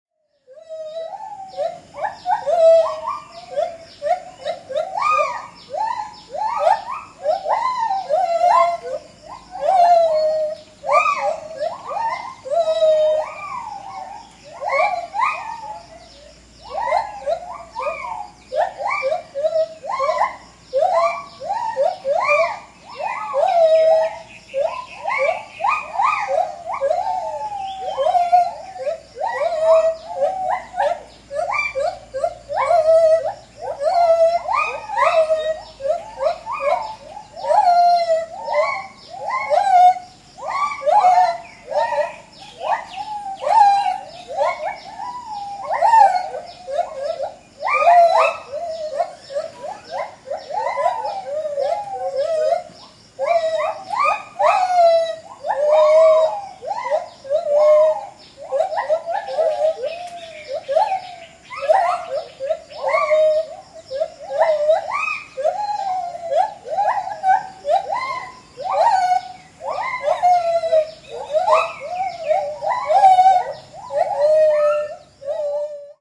Здесь вы найдете разнообразные крики, песни и коммуникационные сигналы этих обезьян, записанные в естественной среде обитания.
Группа гиббонов издает звуки ртом